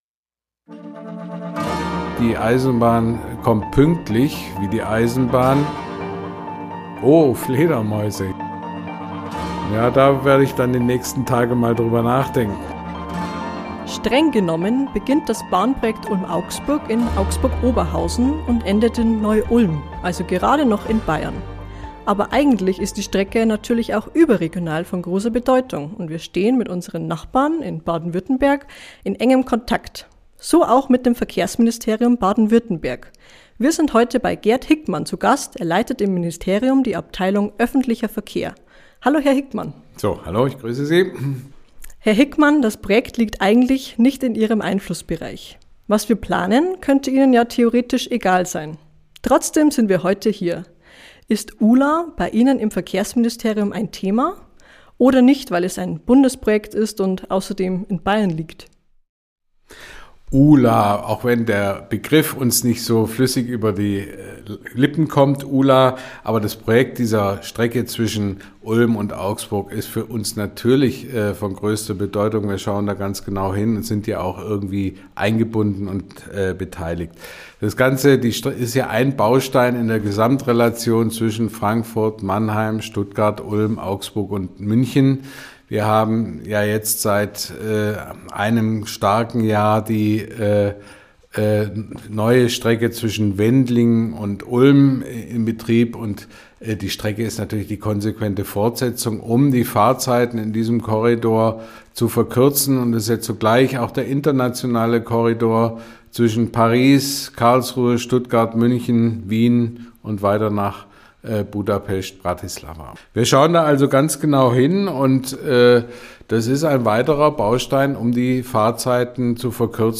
Das ist der Grund, warum auch Vertreter aus dem Verkehrsministerium in Baden-Württemberg mit am Tisch sitzen. Ein Gespräch